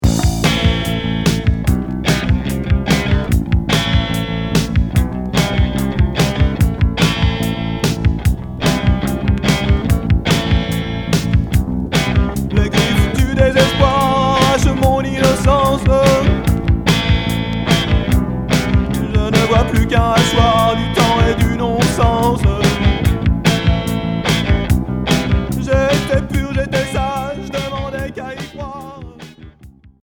Cold wave